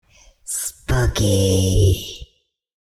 Download Free Spooky Sound Effects
Download Spooky sound effect for free.